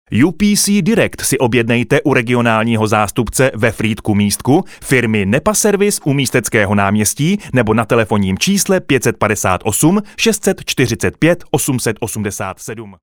Sprecher tschechisch für TV / Rundfunk / Industrie Werbung.
Sprechprobe: Industrie (Muttersprache):
Professionell voice over artist from Czech.